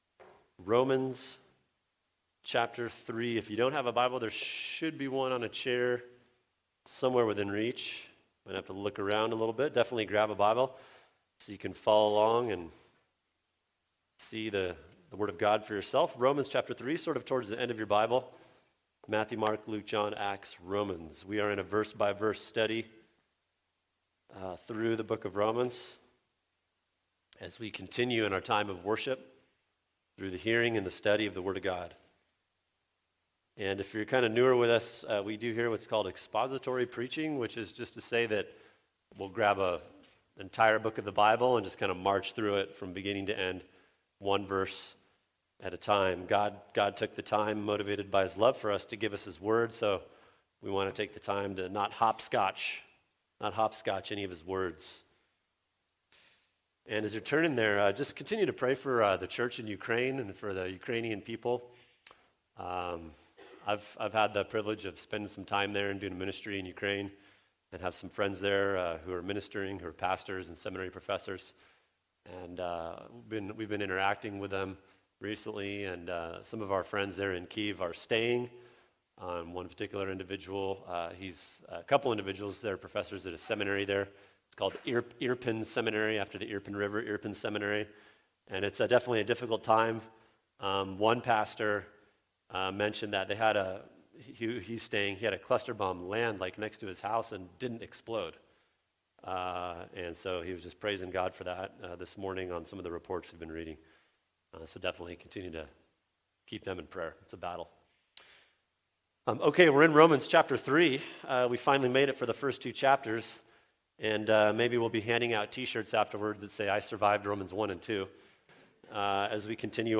[sermon] Romans 3:1-8 Answering Objections to the Faith | Cornerstone Church - Jackson Hole